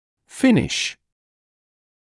[‘fɪnɪʃ][‘финиш]заканчивать, завершать